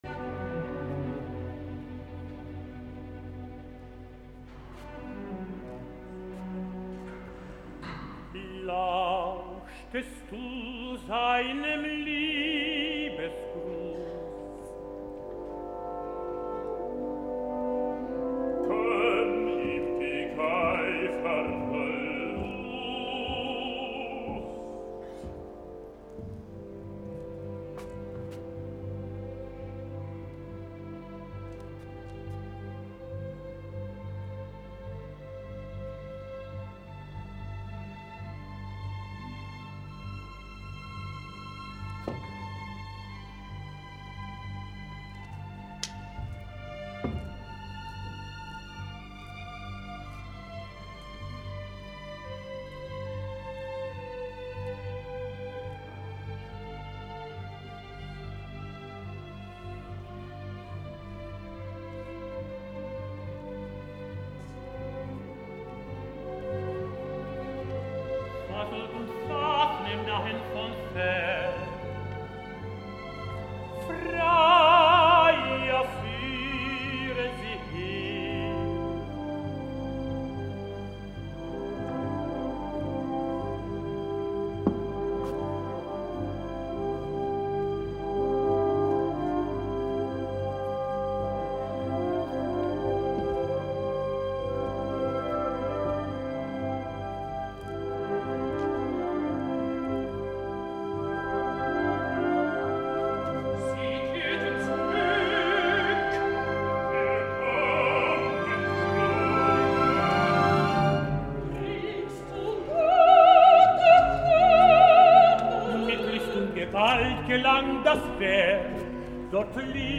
Us mentiria si us digués que l’he escoltat. Es pot dir que Wotan i Fricka s’acaben d’instal·lar al Walhalla després d’obtenir les claus, i ja teniu aquí la primera representació de Das Rheingold que va tenir lloc ahir dissabte a l’Òpera Estatal de Baviera, en la nova producció a càrrec de Kent Nagano en la direcció musical i Andreas Kriegenburg en l’escènica, al capdavant d’aquest repartiment:
Producció de Andreas Kriegenburg Foto © Bayerische Staatsoper Així, com aquell qui no diu la cosa, us diré que al principi se sent molt l’apuntador, en l’escena amb Alberic i les nenes. Per altre part l’entrada en pianissimo de l’orquestra amb el difícil, climàtic i cabdal crescendo no m’ha agradat gaire, però després la cosa sona força millor.